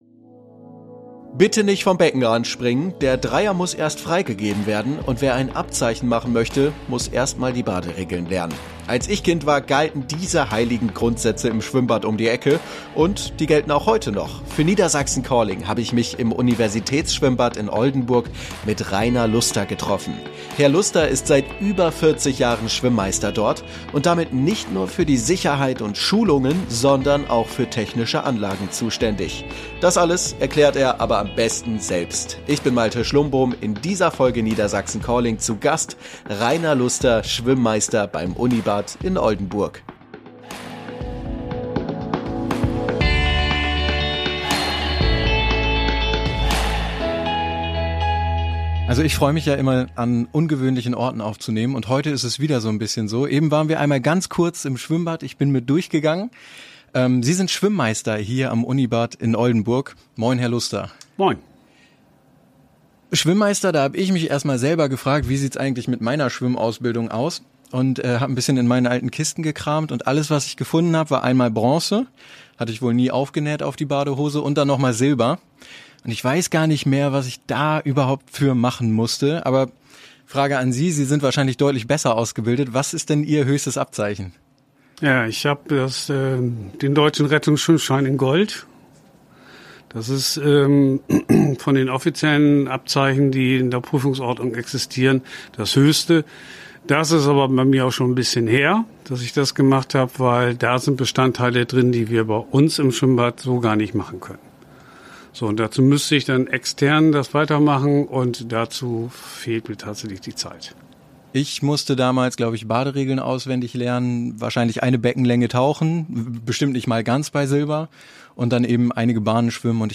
Podcastaufnahme an einem ungewöhnlichen Ort: